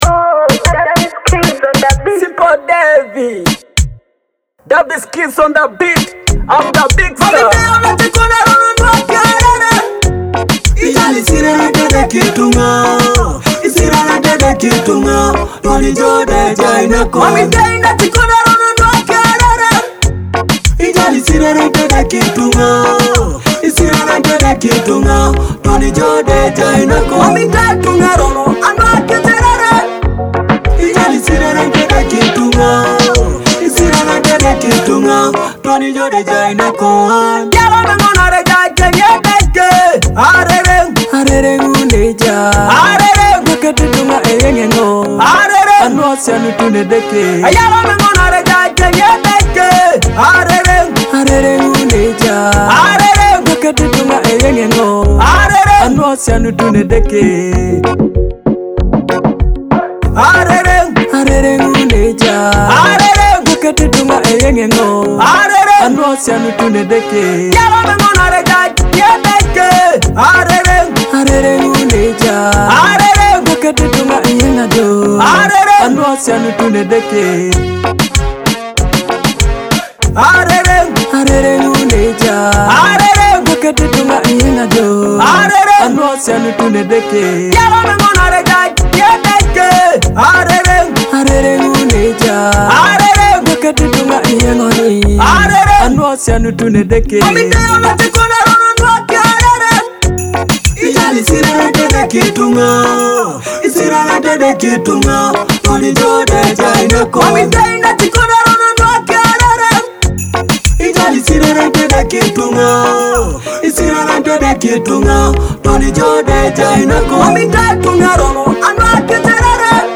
With its catchy beats, energetic rhythm, and vibrant vocals